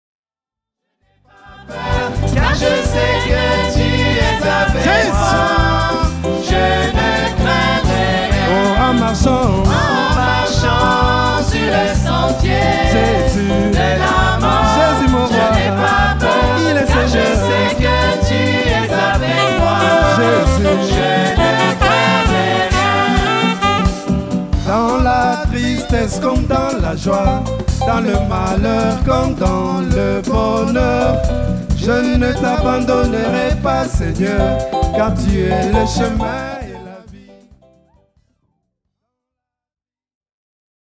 Enregistrement public réalisé à Genève en 2006